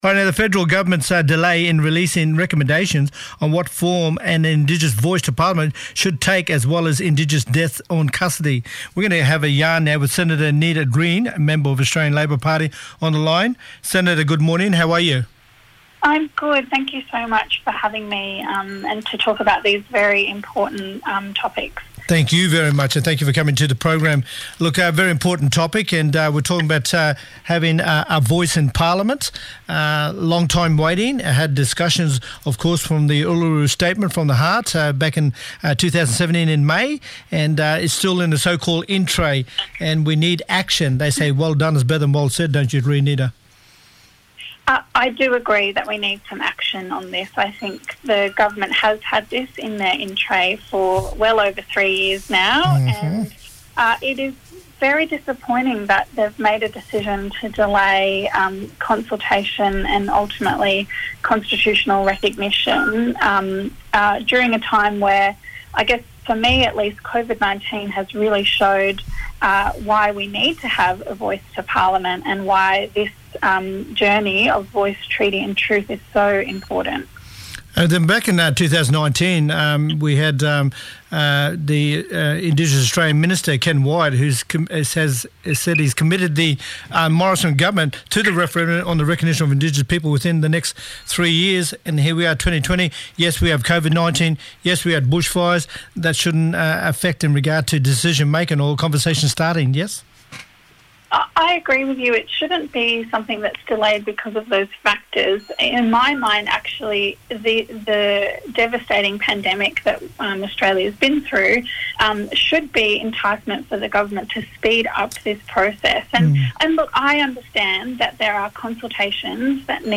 Nita Green – Senator for Queensland talking about the federal government’s delay in releasing recommendations on what form an Indigenous voice to parliament should take as well as Indigenous deaths in custody.